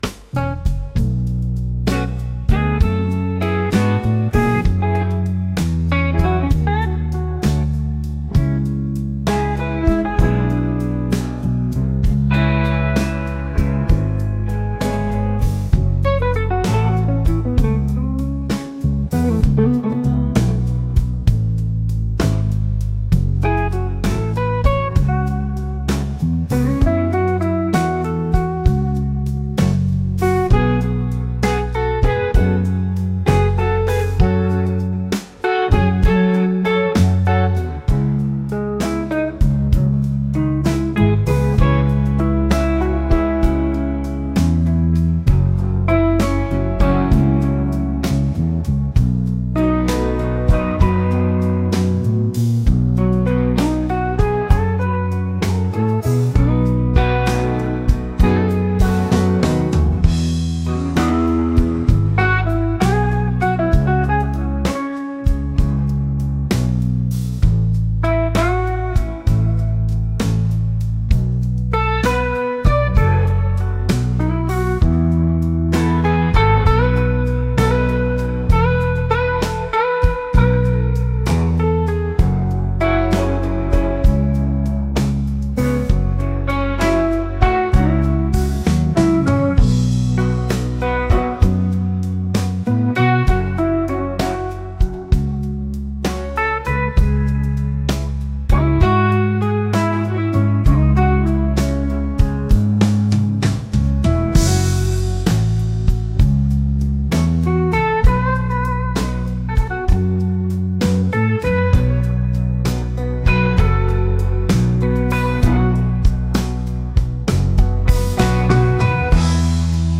soulful | groovy